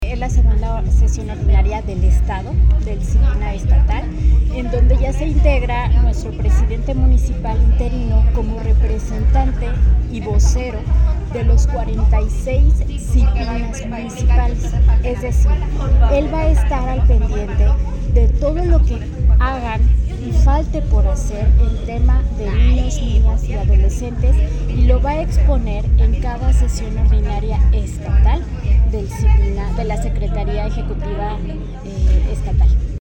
AudioBoletines
Lili Torrero, directora del DIF